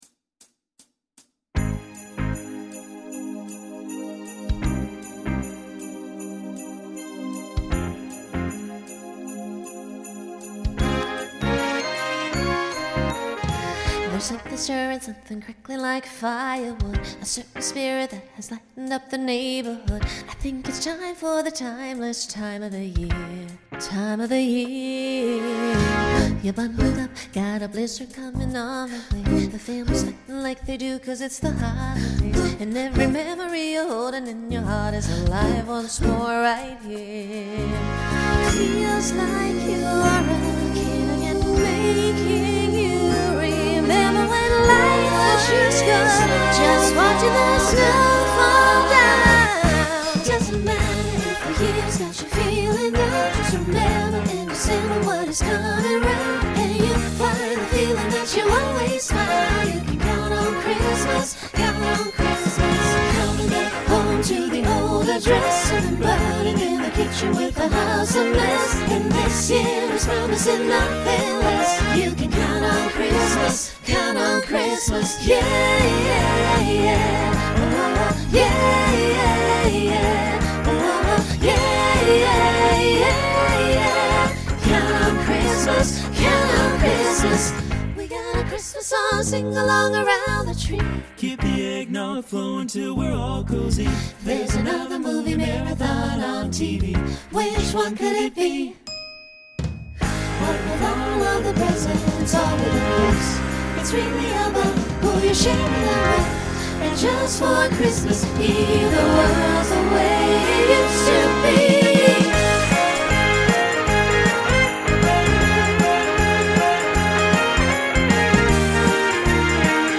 Genre Holiday , Pop/Dance Instrumental combo
Opener Voicing SATB